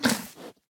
Minecraft Version Minecraft Version latest Latest Release | Latest Snapshot latest / assets / minecraft / sounds / mob / mooshroom / eat2.ogg Compare With Compare With Latest Release | Latest Snapshot
eat2.ogg